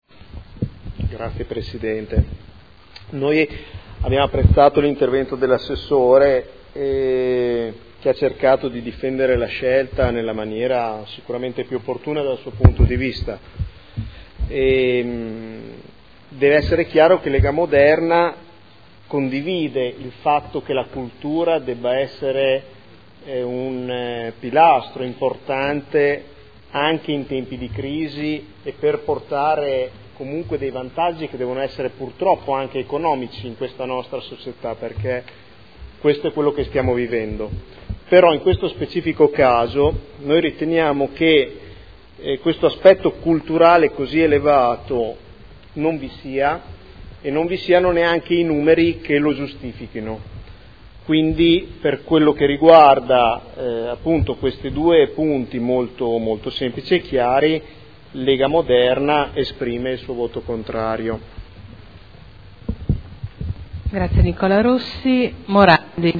Nicola Rossi — Sito Audio Consiglio Comunale
Dichiarazione di voto su emendamento e proposta di deliberazione. Fotomuseo Panini e Fondazione Fotografia - Indirizzi per la costituzione di una nuova Fondazione